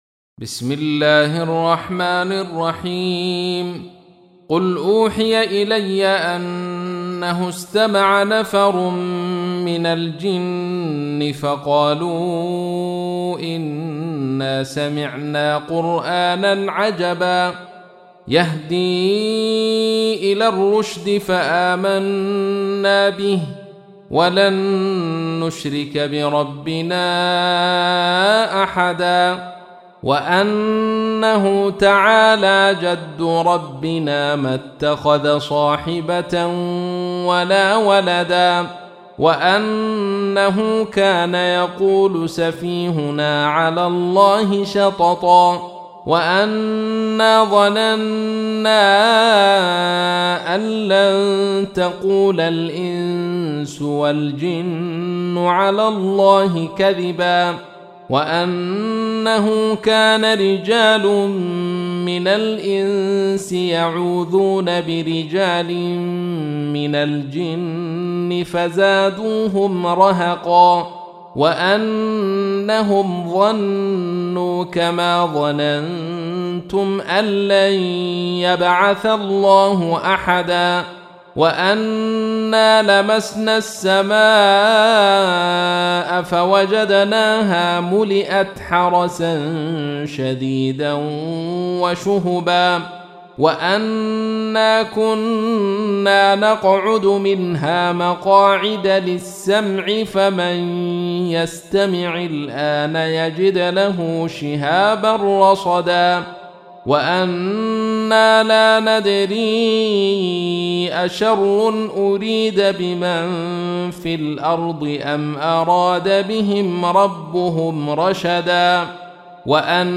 تحميل : 72. سورة الجن / القارئ عبد الرشيد صوفي / القرآن الكريم / موقع يا حسين